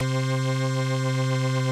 Organ - Boston.wav